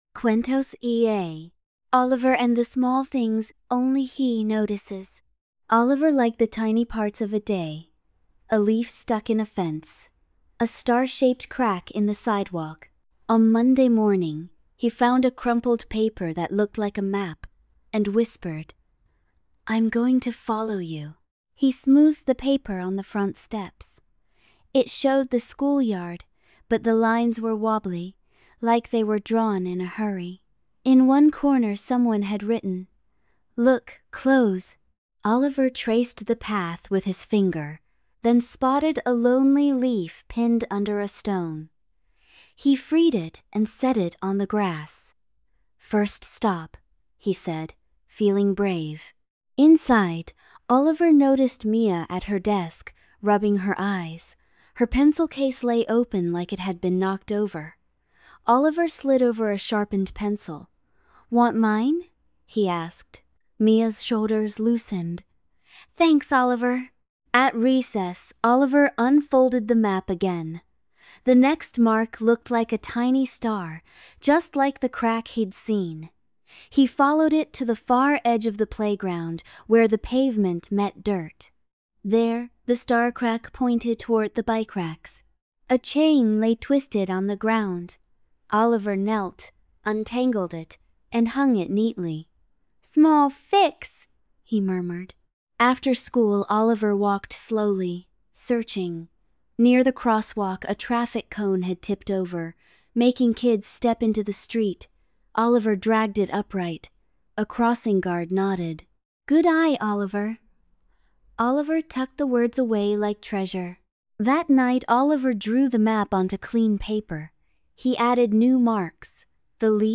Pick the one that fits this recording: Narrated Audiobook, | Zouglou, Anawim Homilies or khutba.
Narrated Audiobook